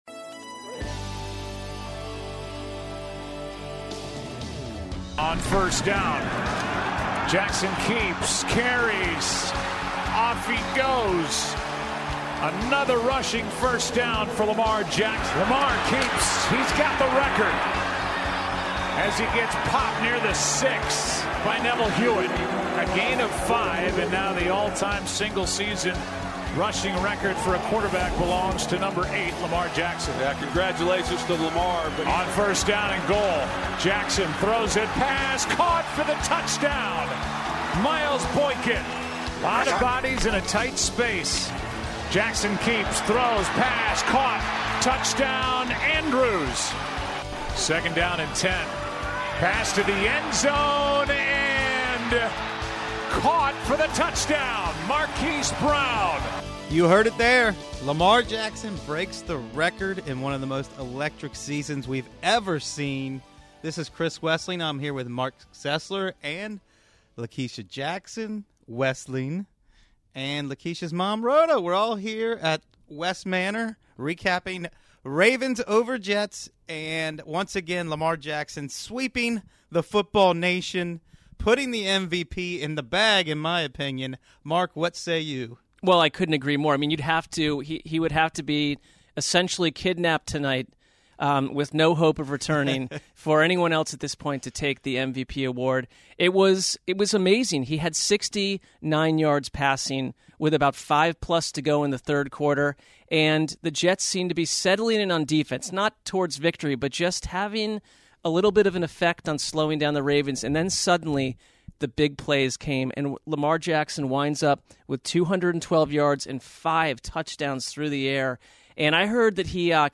The crowd in Baltimore chants MVP! MVP! MVP!